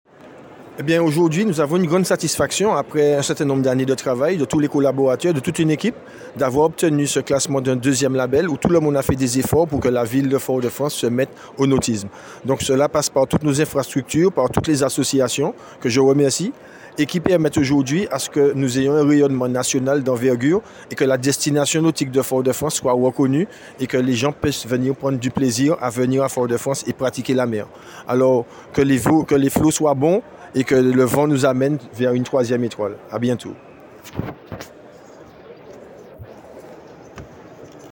Interview de Luc Jouye de Grandmaison – Conseiller Municipal Délégué au tourisme et au nautisme